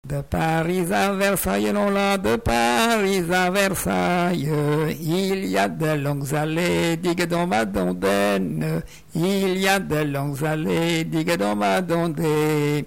chanteur(s), chant, chanson, chansonnette
Genre laisse
Pièce musicale inédite